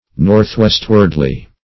Search Result for " northwestwardly" : Wordnet 3.0 ADVERB (1) 1. in a northwestward direction ; [syn: northwestward , northwestwardly ] The Collaborative International Dictionary of English v.0.48: Northwestward \North`west"ward\, Northwestwardly \North`west"ward*ly\, adv. Toward the northwest.
northwestwardly.mp3